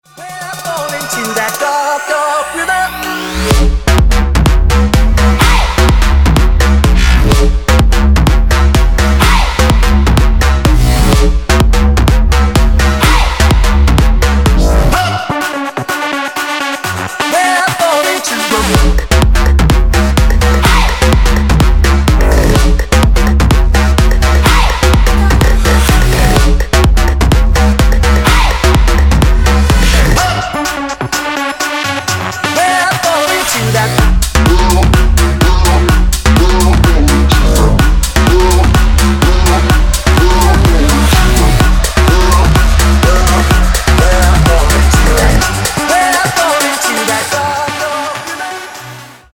• Качество: 160, Stereo
club
house
electro house